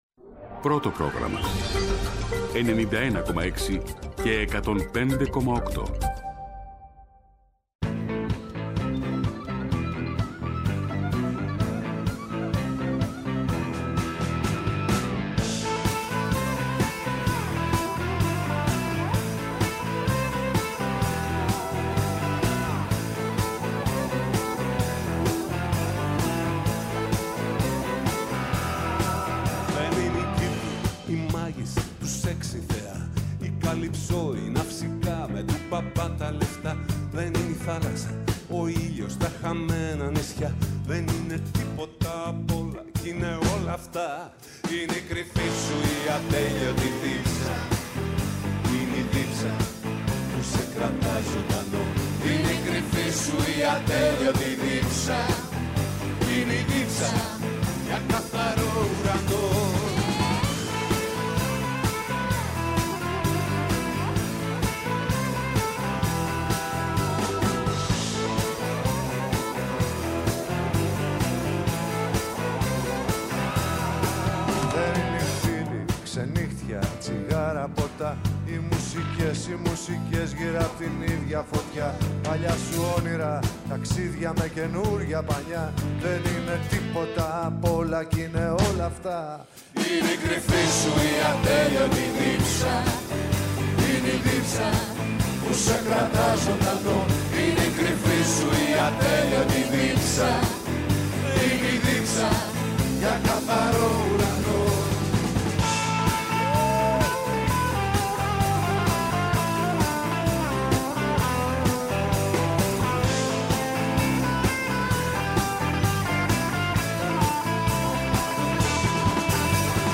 Τα θέματα που μας απασχόλησαν, μέσα από ηχητικά αποσπάσματα αλλά και ζωντανές συνεντεύξεις .